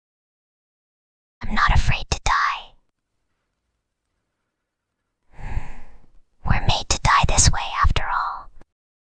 File:Whispering Girl 13.mp3
Whispering_Girl_13.mp3